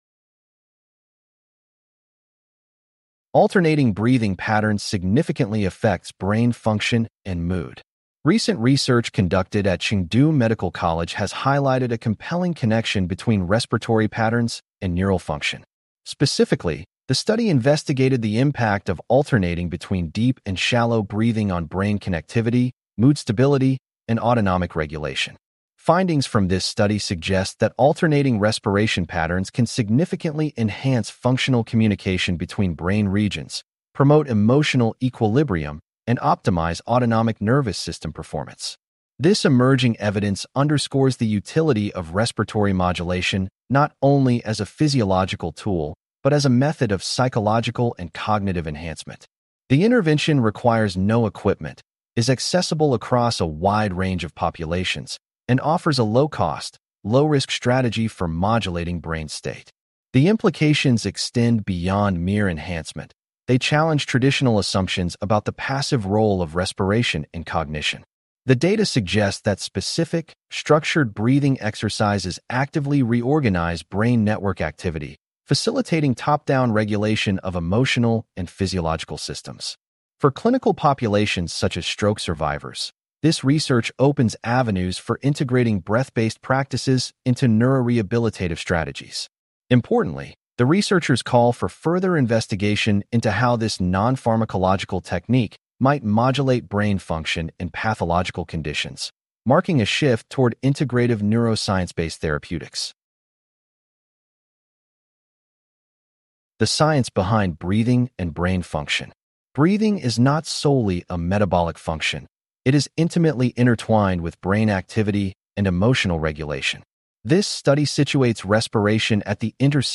CLICK TO HEAR THIS POST NARRATED What Is The Takeaway?